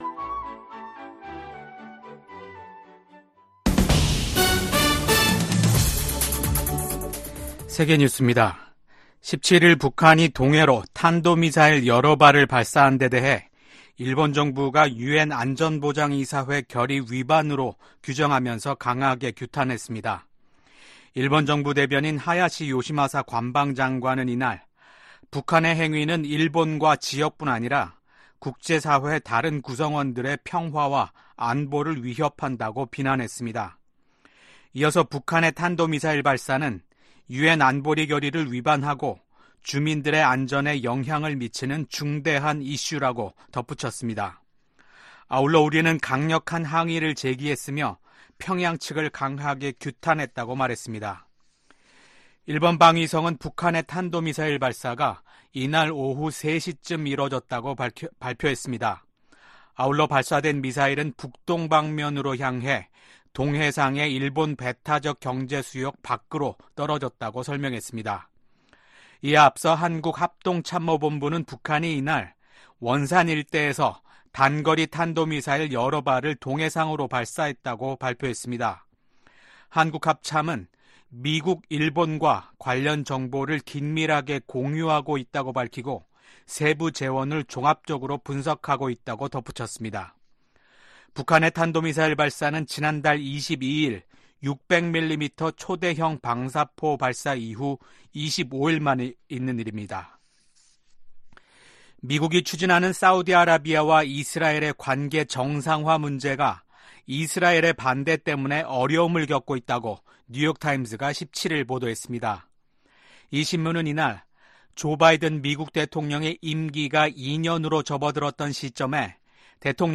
VOA 한국어 아침 뉴스 프로그램 '워싱턴 뉴스 광장' 2024년 5월 18일 방송입니다. 북한이 오늘 동해상으로 단거리 미사일 여러 발을 발사했습니다.